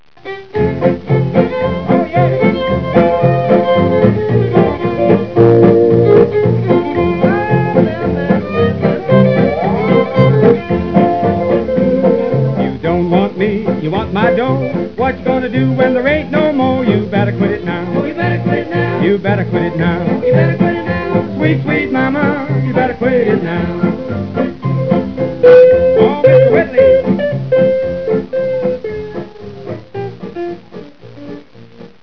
Go back to the  Western Swing  page.